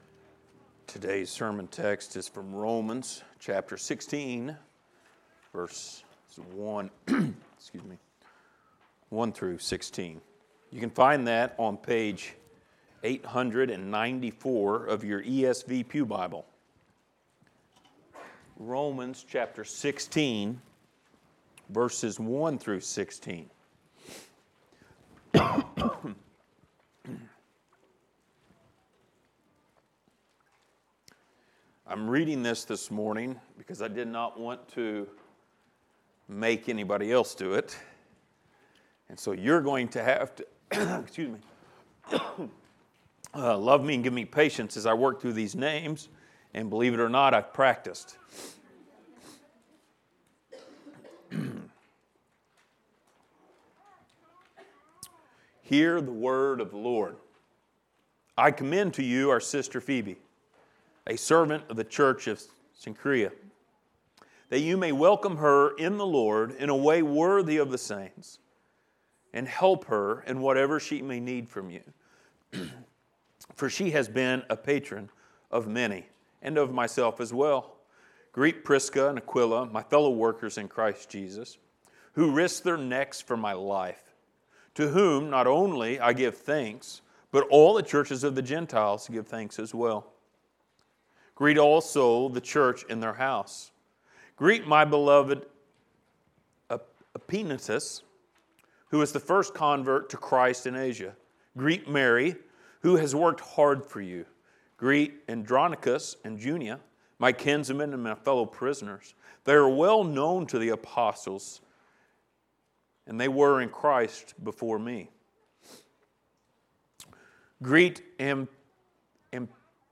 Passage: Romans 16:1-16 Service Type: Sunday Morning